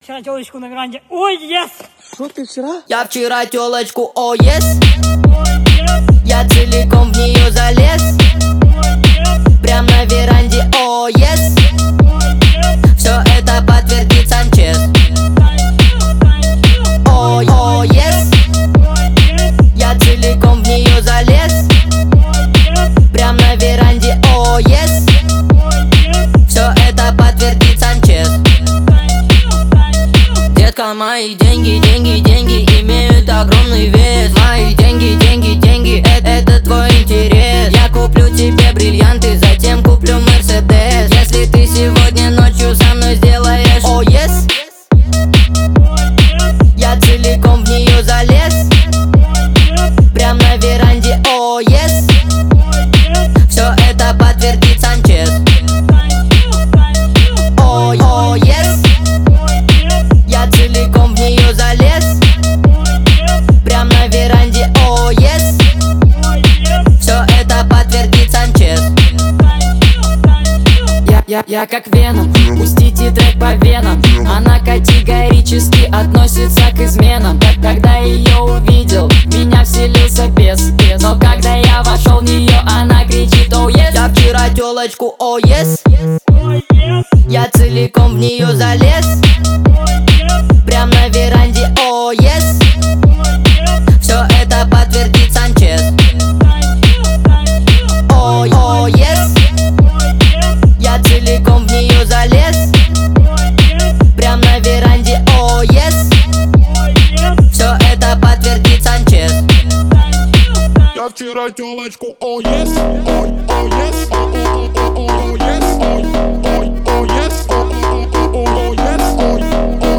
• Жанр: Русские